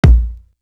Guns Is Razors Kick.wav